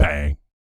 BS BANG 02.wav